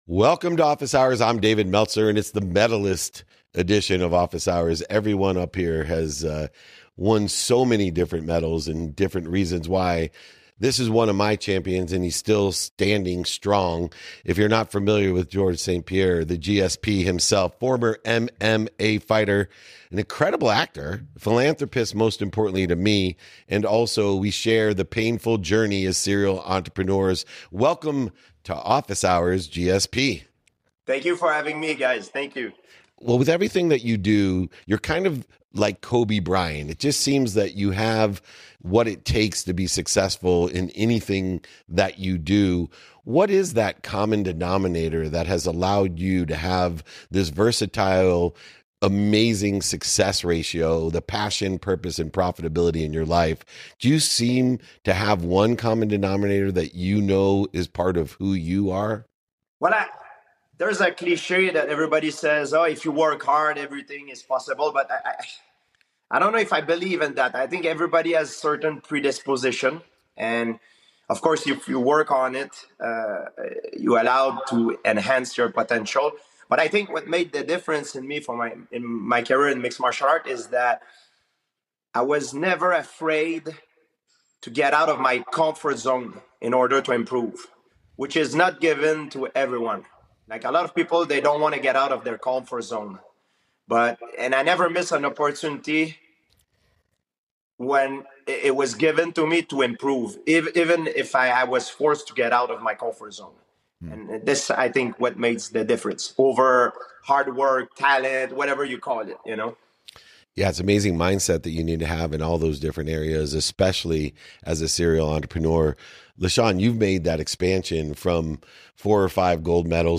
In today’s episode, I sit down with Georges St-Pierre, the legendary MMA champion and actor celebrated for his dominance across multiple UFC weight classes.